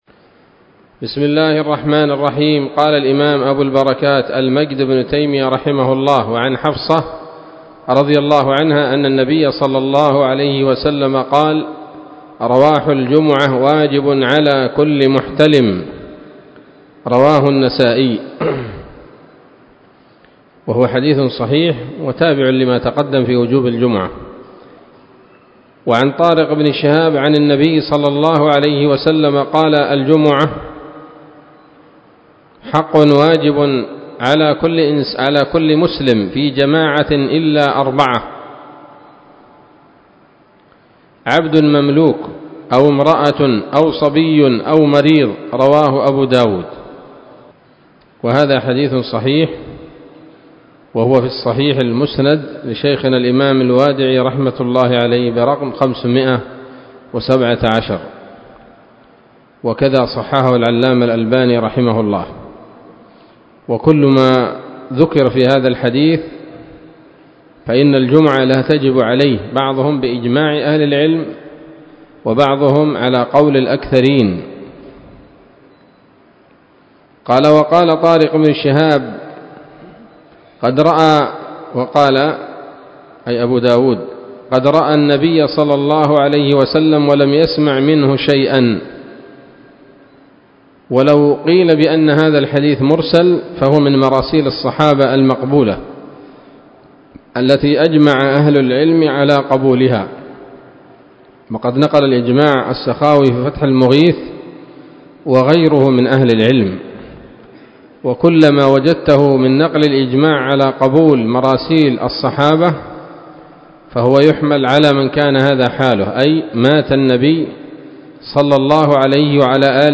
الدرس الرابع من ‌‌‌‌أَبْوَاب الجمعة من نيل الأوطار